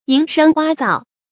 蝇声蛙躁 yíng shēng wā zào 成语解释 苍蝇、青蛙无休止地叫。